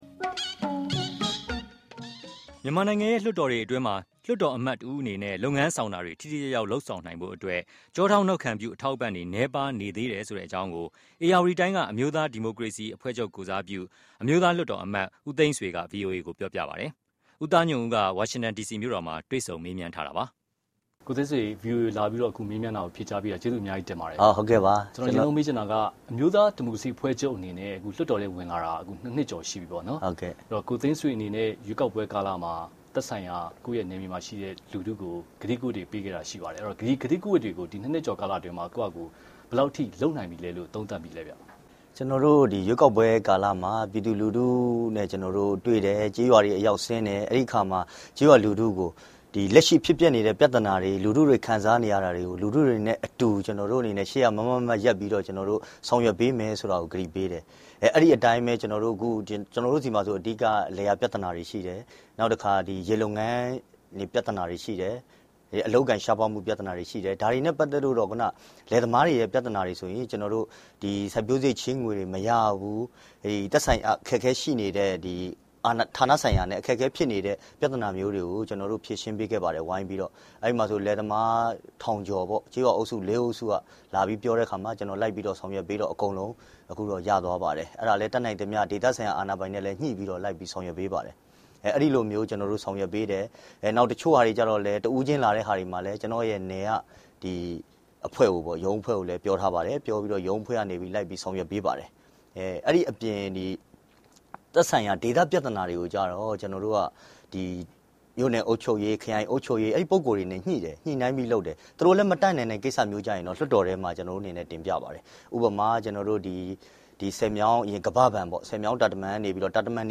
လွှတ်တော်အမတ် ဦးသိန်းဆွေနဲ့ မေးမြန်းခန်း